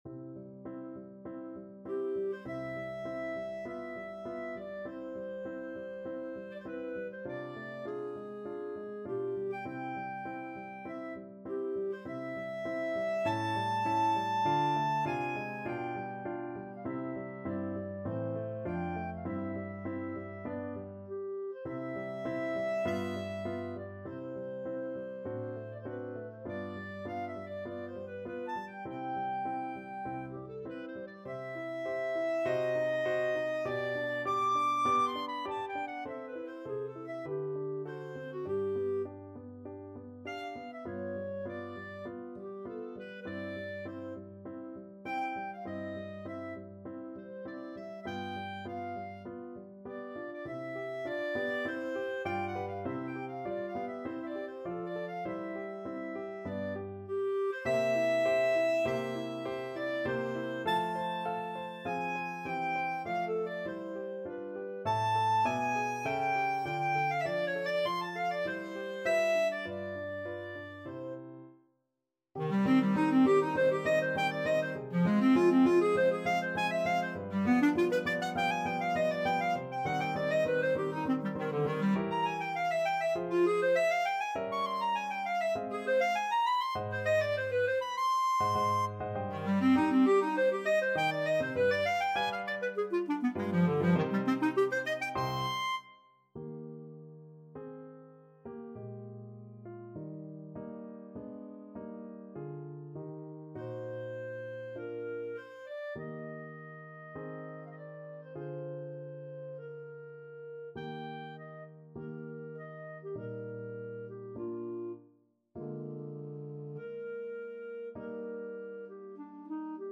ClarinetPiano
4/4 (View more 4/4 Music)
C major (Sounding Pitch) D major (Clarinet in Bb) (View more C major Music for Clarinet )
Clarinet  (View more Intermediate Clarinet Music)
Classical (View more Classical Clarinet Music)